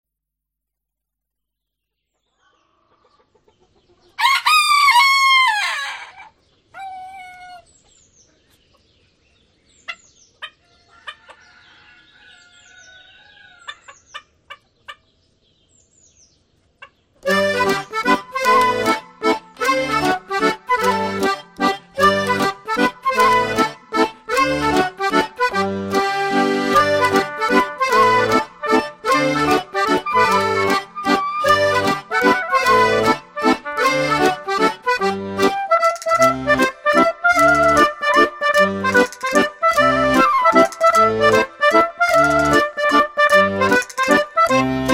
Folk / Celta/ World Music